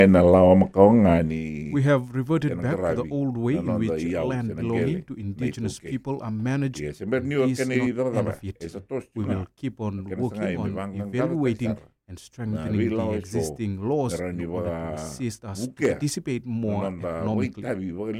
Prime Minister Sitiveni Rabuka, while speaking on Radio Fiji One’s “Na Noda Paraiminisita” program, stressed this move.